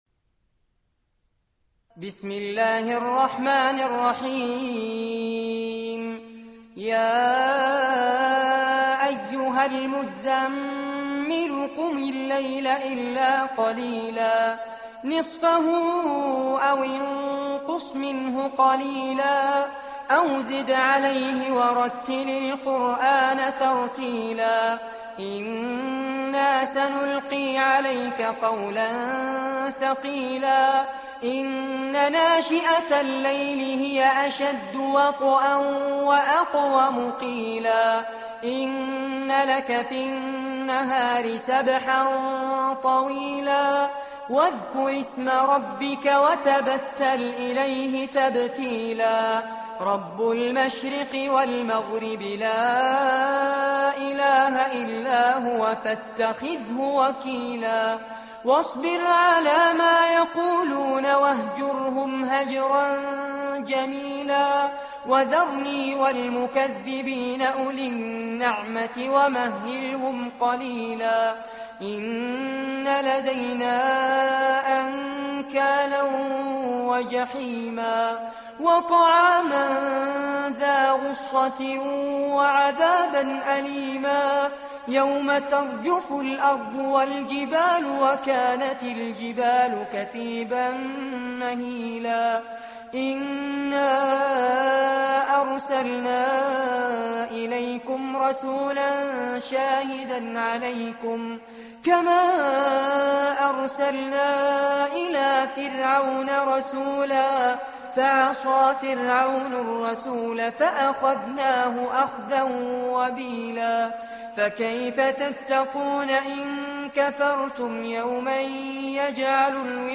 عنوان المادة سورة المزمل- المصحف المرتل كاملاً لفضيلة الشيخ فارس عباد جودة عالية